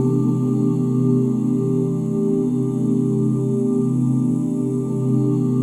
OOH CMAJ9.wav